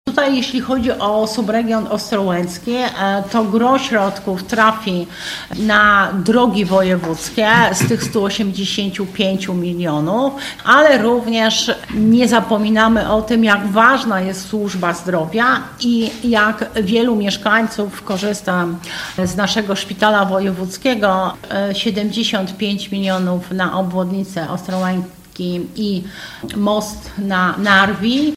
Zaprezentowała je na dzisiejszej konferencji prasowej członkini zarządu woj. mazowieckiego, Janina Ewa Orzełowska: